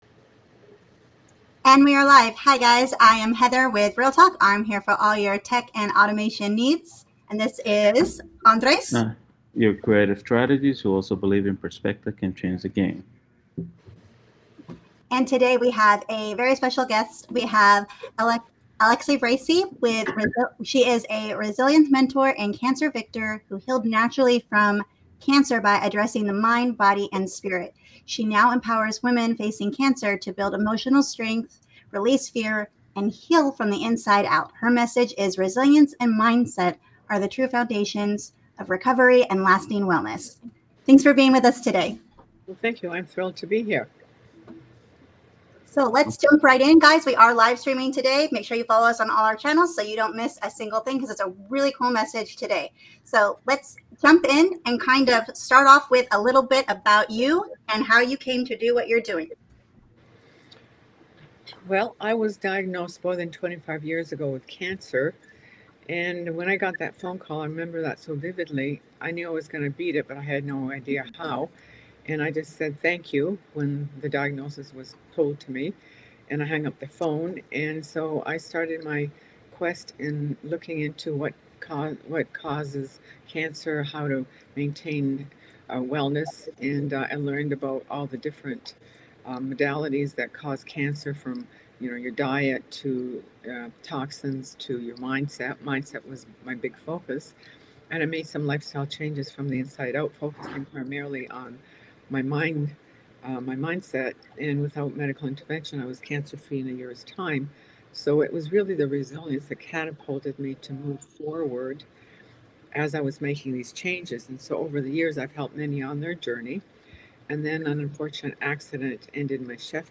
Entrepreneurship is messy, business isn't always pretty, and we're here to talk about all of it. Join us LIVE as we break down real struggles, real wins, and the systems that actually move the needle for entrepreneurs and business owners.